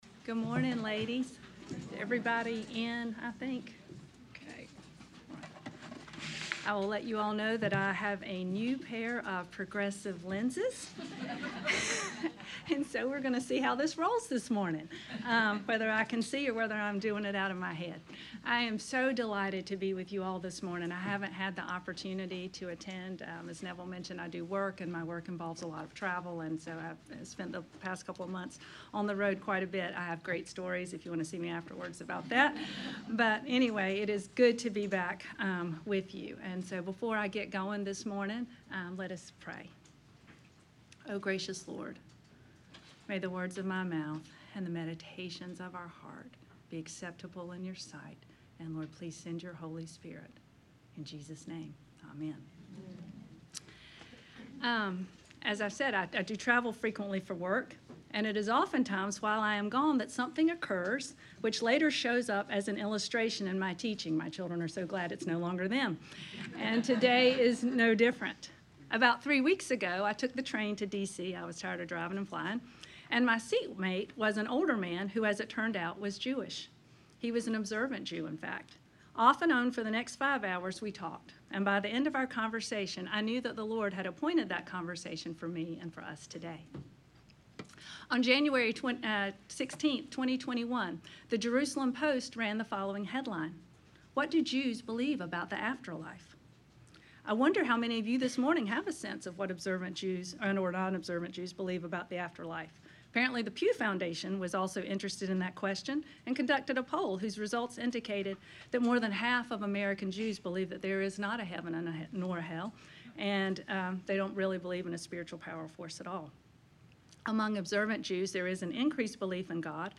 WOW-Lecture-2-2024.mp3